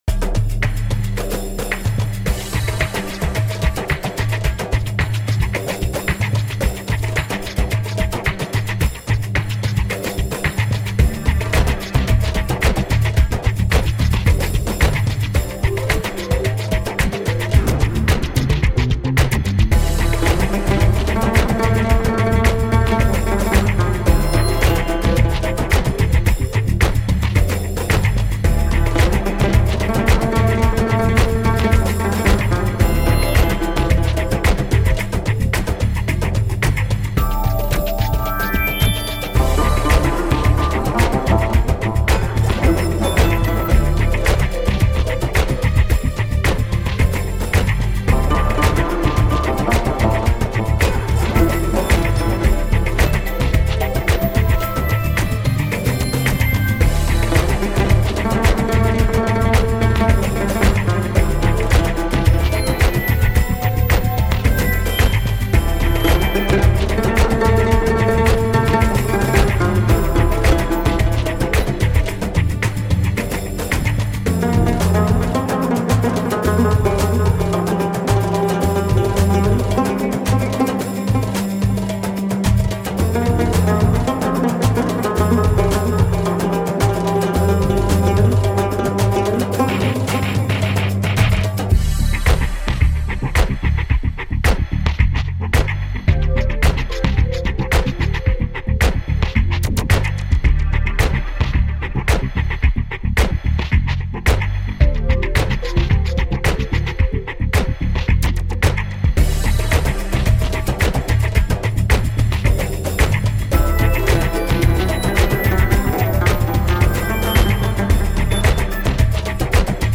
Passionate eastern percussion.